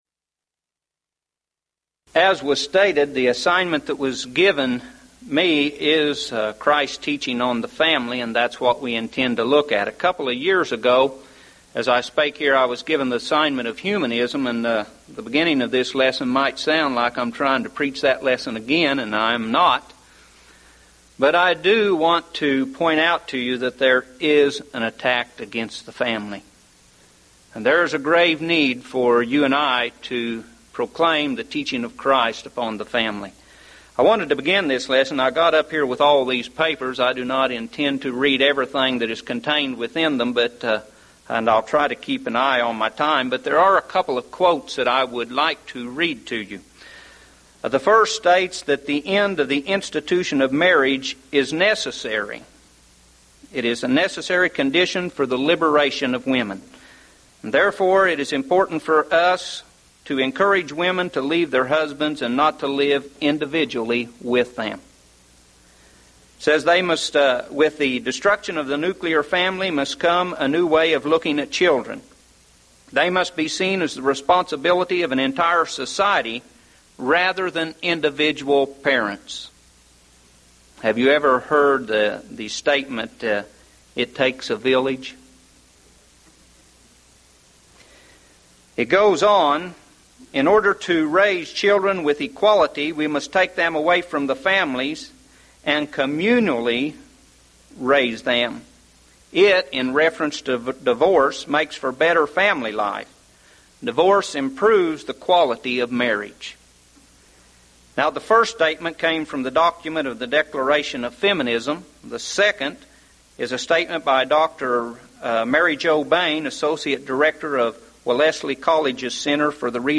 Event: 1998 Mid-West Lectures